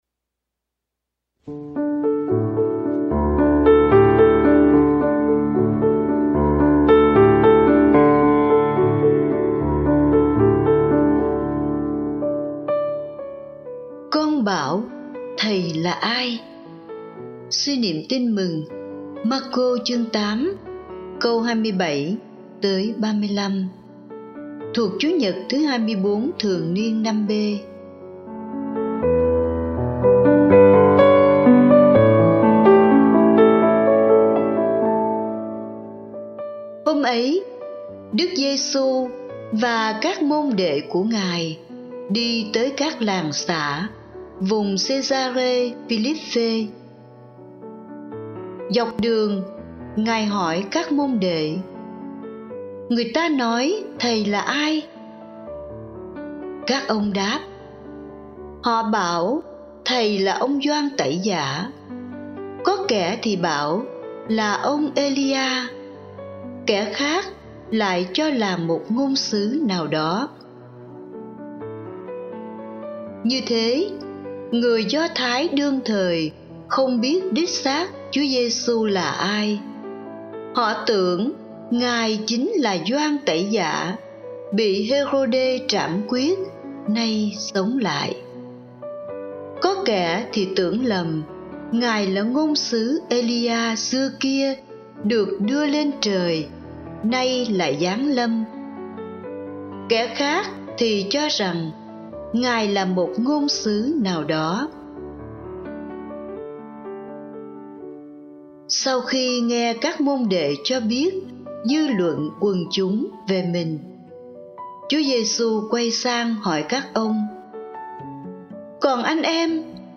Bài giãng lễ Chúa nhật 25 mùa thường niên B - 2018